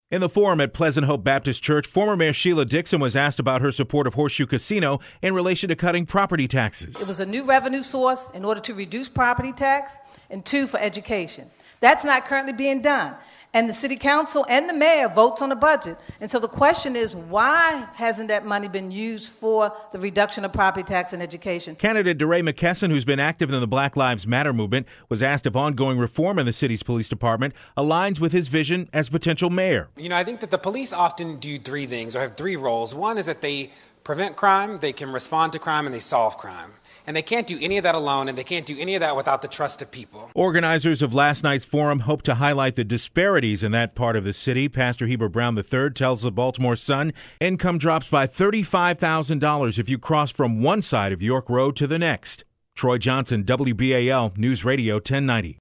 A report I filed in February of 2016.